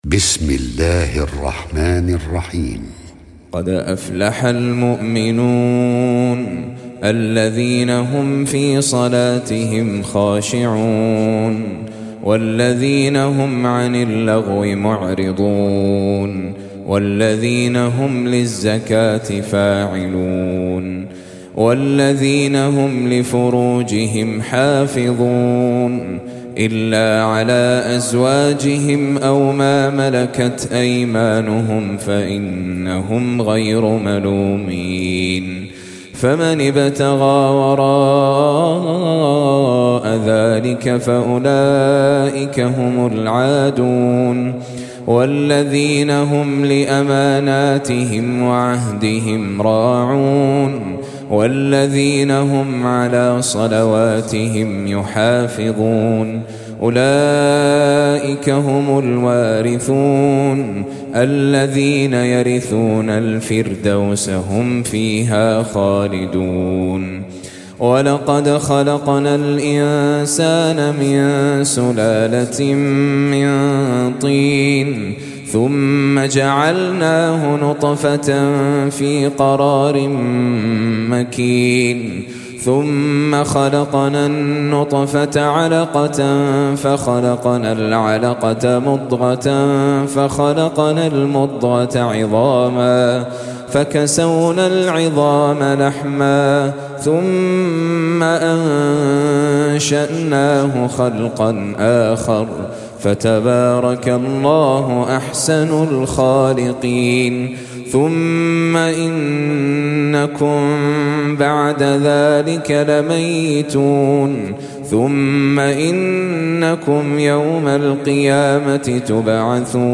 Surah Sequence تتابع السورة Download Surah حمّل السورة Reciting Murattalah Audio for 23.